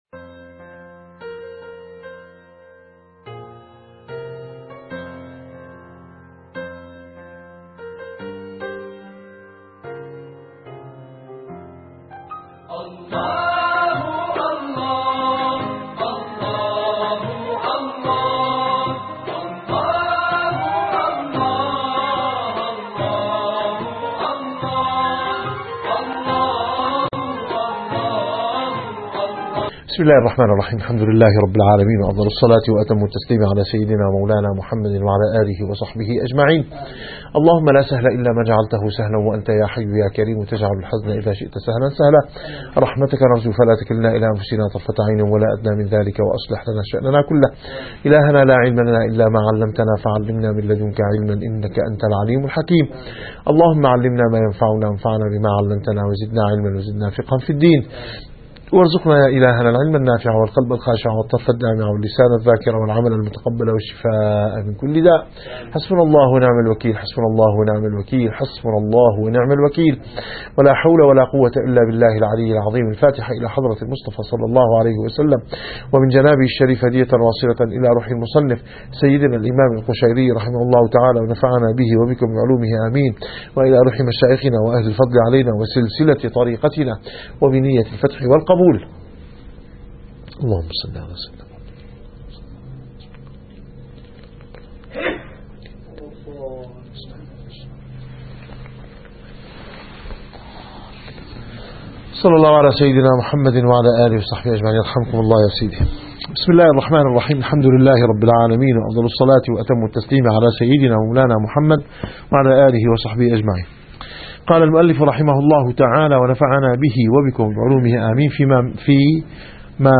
الرسالة القشيرية / الدرس الثالث والأربعون بعد المئة.